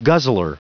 Prononciation du mot guzzler en anglais (fichier audio)
Prononciation du mot : guzzler